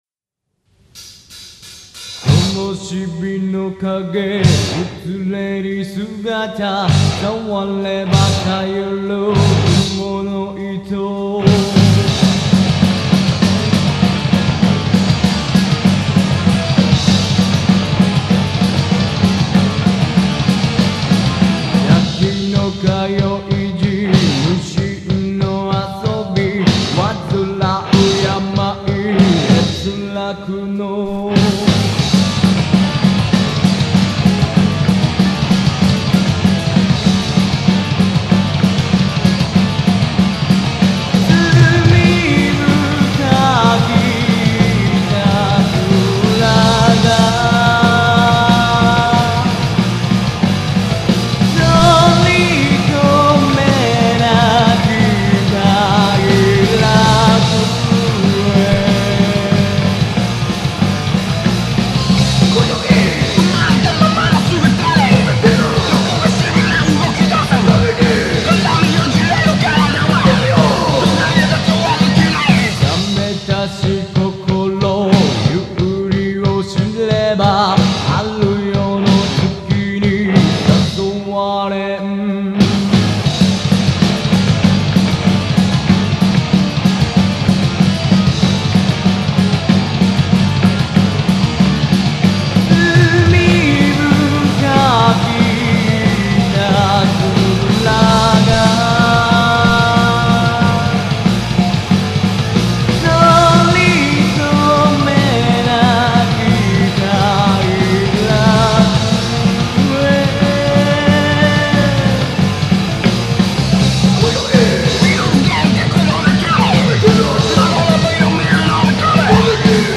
HARD ROCK系
＊ボリューム注意